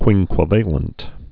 (kwĭngkwə-vālənt)